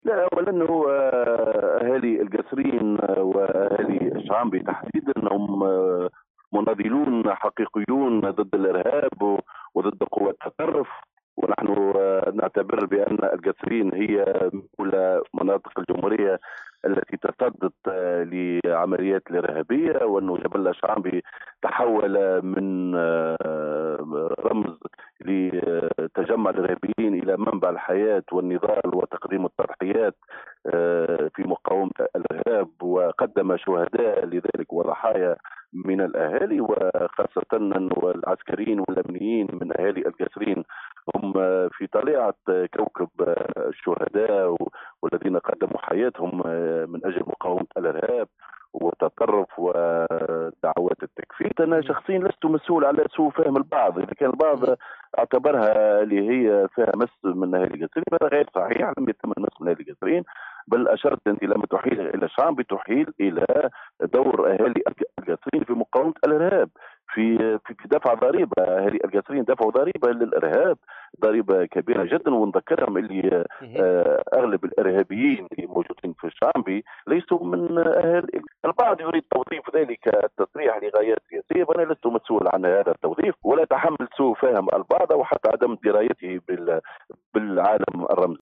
اثر تصريحه مؤخرا باحدى الاذاعات الخاصة و الذي اثار غضب اهالي القصرين اكد النائب بمجلس نواب الشعب خالد الكريشي صباح اليوم 8 مارس 2020في مداخلة ببرنامج  « compte rendu » على موجات السيليوم اف ام ان القصرين اهي قلعة النضال و ا ن اهلها هم رمز المقاومة ضد الارهاب و قدمو شهداء فداءا للوطن